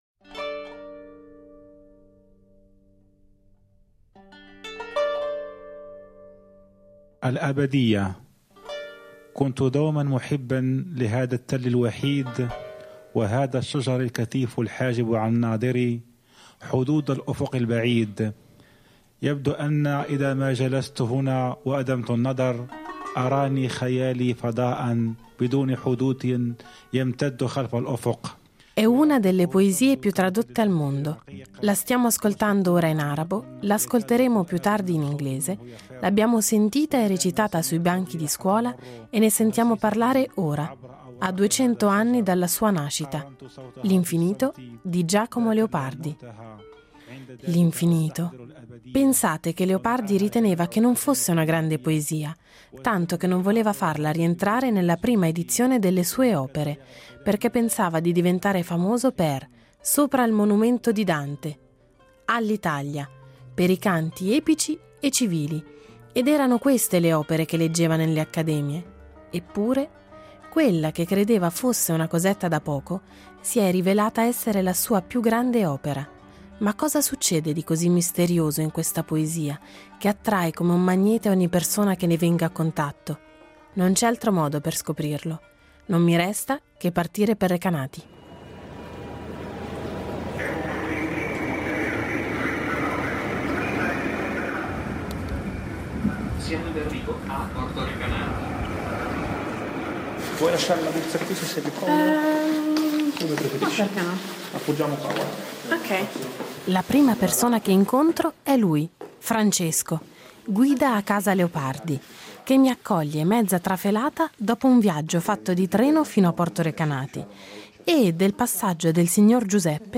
E perché "L'Infinito" mette i brividi ancora oggi a duecento anni dalla sua stesura? Un viaggio, mille incontri, una missione: conoscere meglio Giacomo, il giovane genio, e il suo Infinito , attraverso un percorso a più voci nella sua Recanati e non solo.